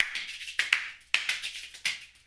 BON105CLAP.wav